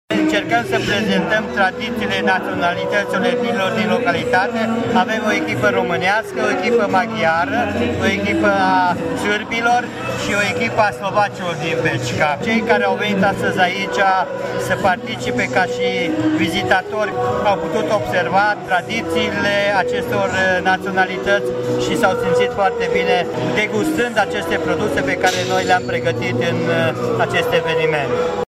A fost și un prilej de a promova, din nou, tradițiile locale, spune primarul Petru Antal.